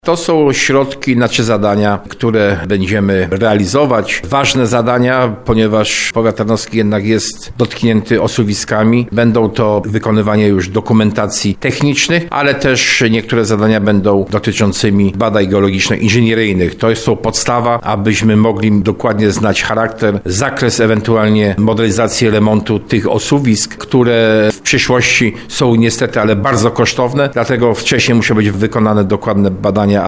Starosta tarnowski Jacek Hudyma zauważa, że opracowanie dokumentacji to dość drogie działania, a późniejsze remonty pochłoną jeszcze więcej środków.